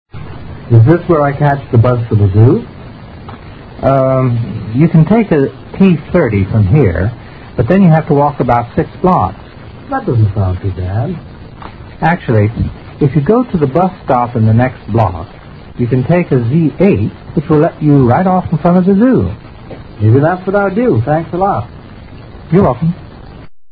Dialogue 9